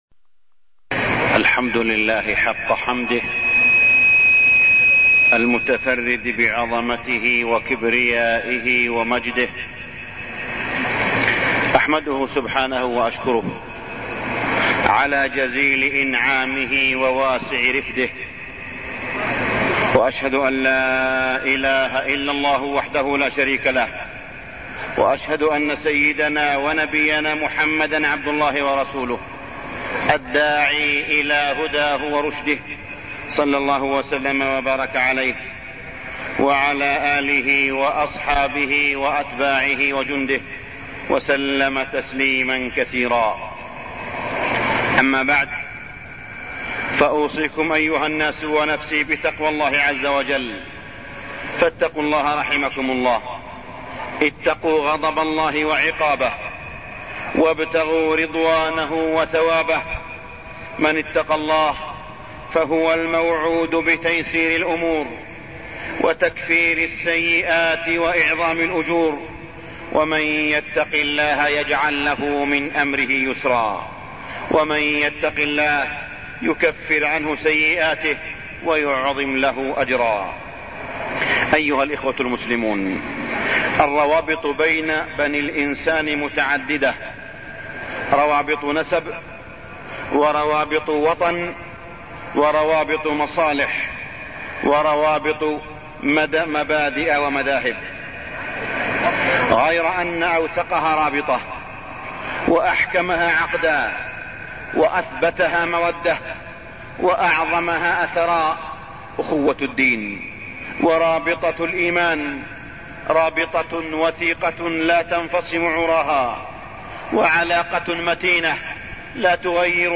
تاريخ النشر ٢٥ شعبان ١٤٢٠ هـ المكان: المسجد الحرام الشيخ: معالي الشيخ أ.د. صالح بن عبدالله بن حميد معالي الشيخ أ.د. صالح بن عبدالله بن حميد الأخوة في الله